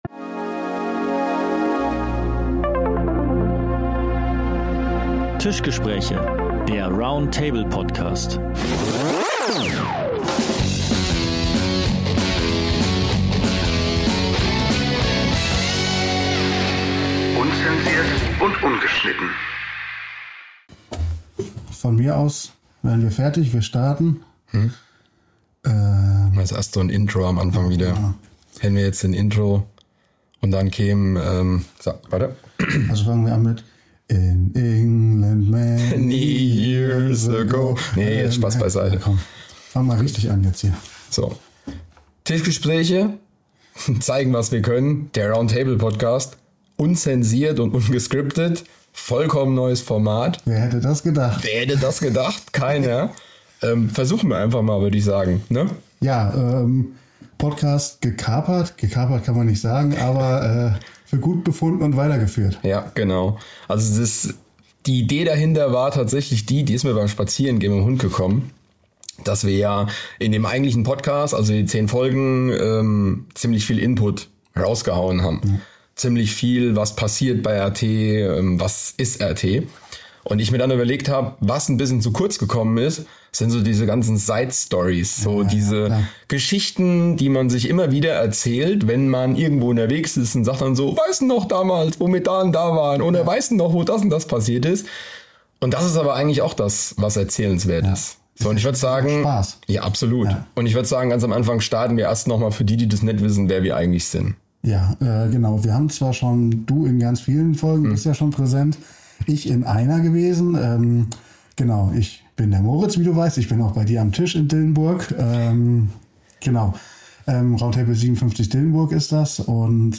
Bonusfolge: Unzensiert & Ungeschnitten ~ Tischgespräche - der Round Table Podcast